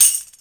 176UK2TAMB-L.wav